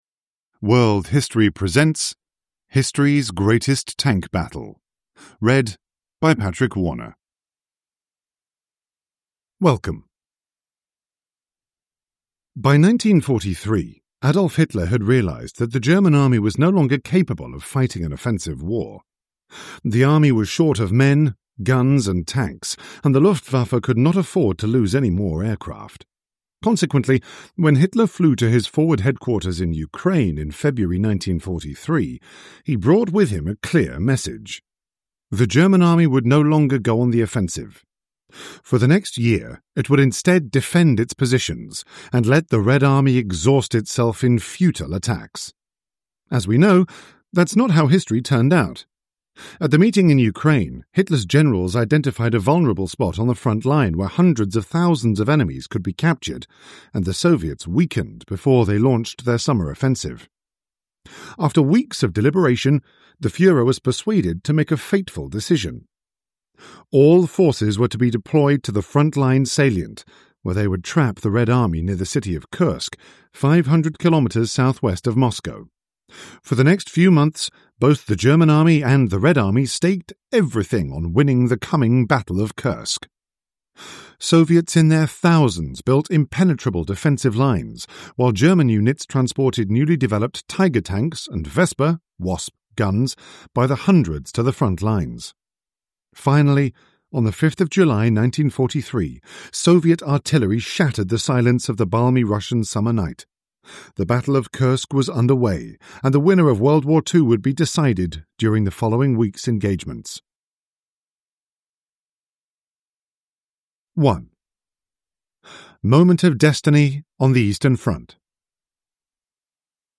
History's Greatest Tank Battle – Ljudbok